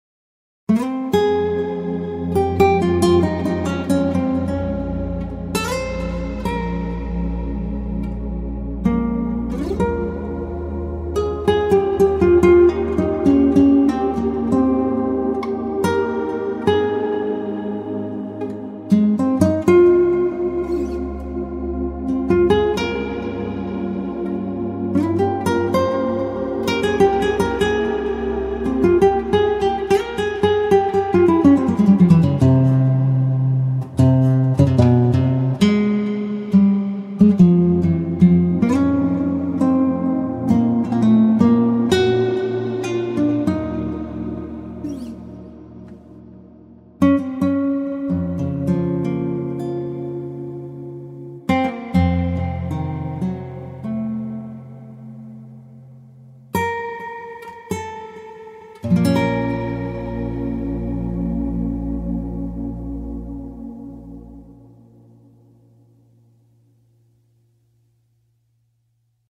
Nylon Guitar plug-in!